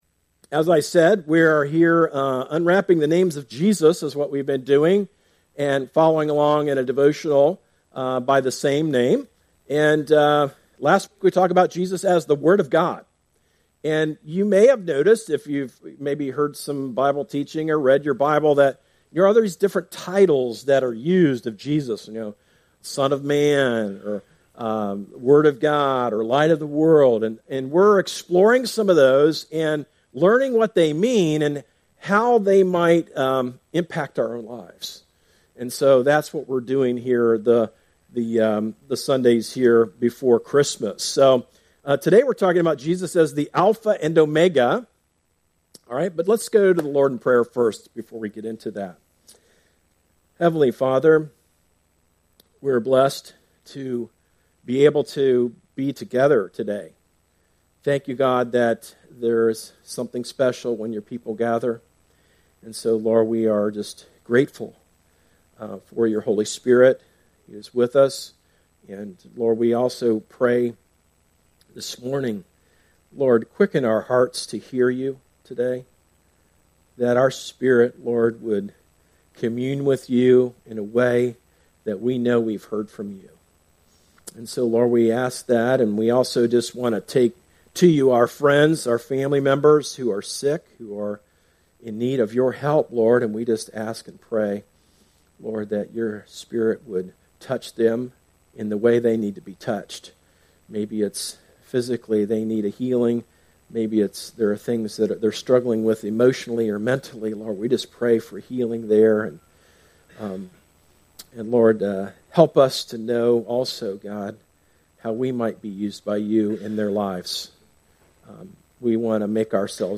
A message from the series "Book Of Esther."